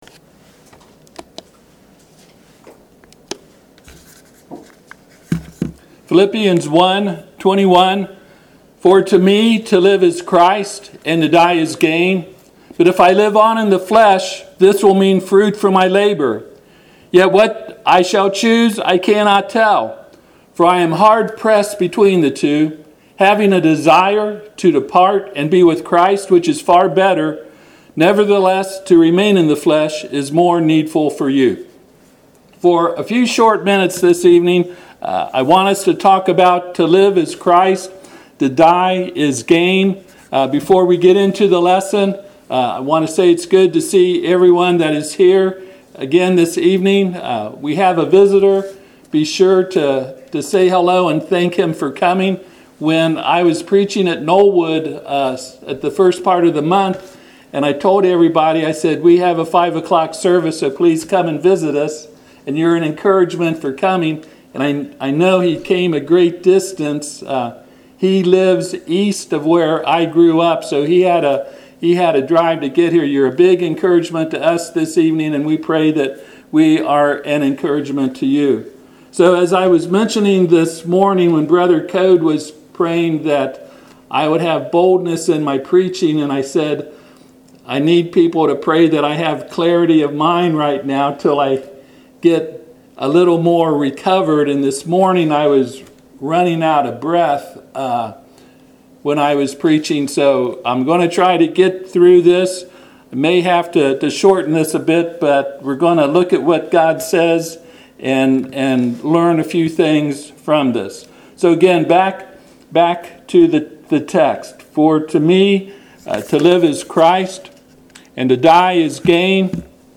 Passage: Philippians 1:21-24 Service Type: Sunday PM